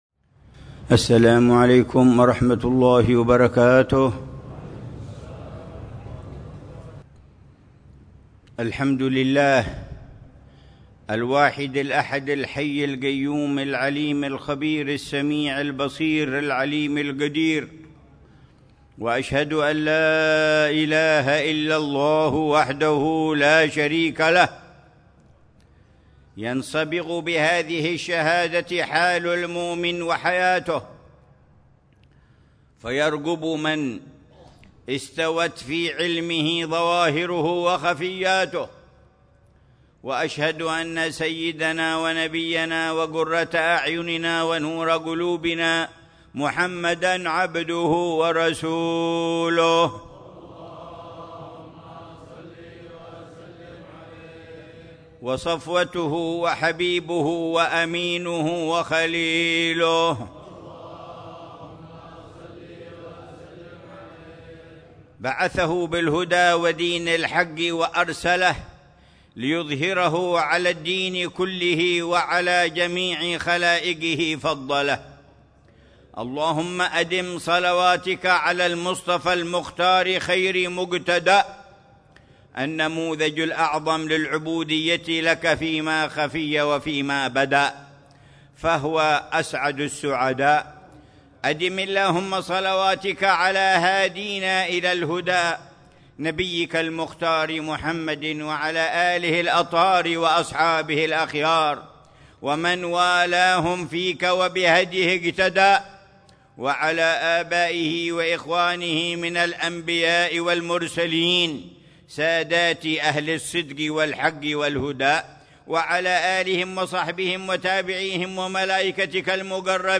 خطبة الجمعة للعلامة الحبيب عمر بن محمد بن حفيظ في جامع الروضة، بحارة الروضة، عيديد، مدينة تريم، 23 جمادى الأولى 1447هـ بعنوان: